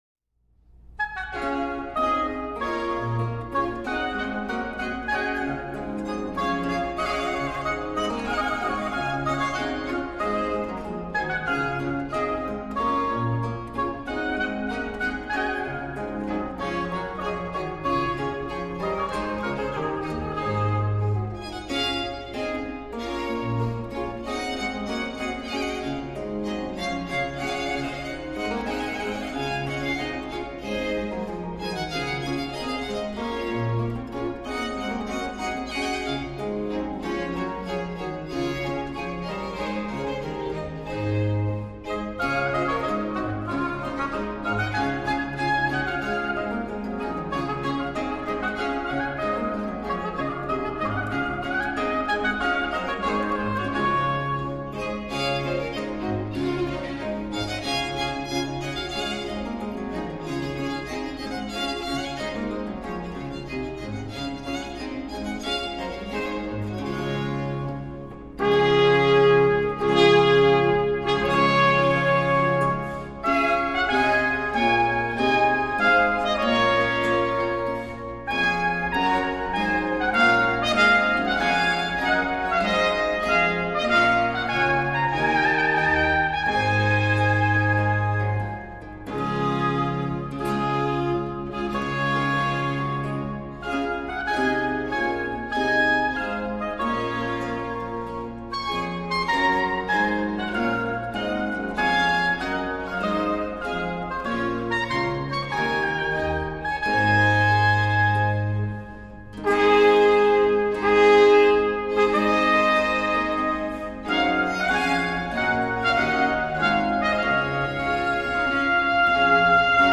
Valveless Baroque Trumpet, Baroque Oboe, Baroque Violins, Theorbo and Positif